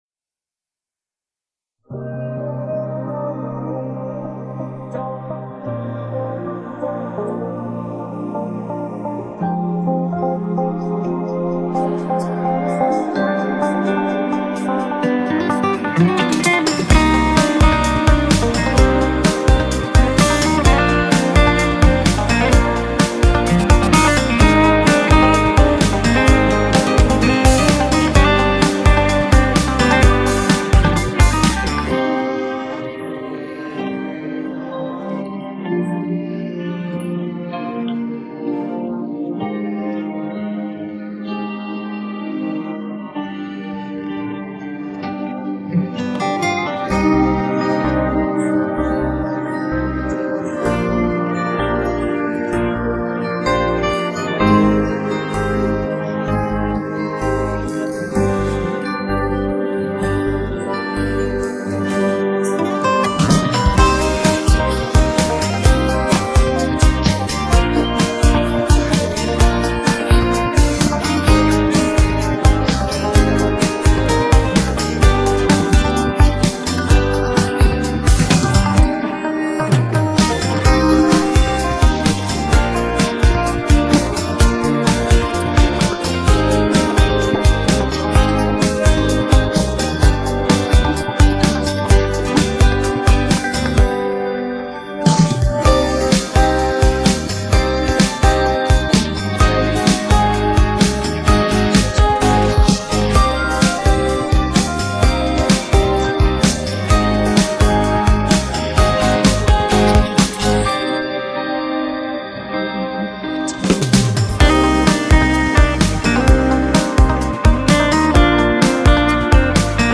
پخش نسخه بیکلام
download-cloud دانلود نسخه بی کلام (KARAOKE)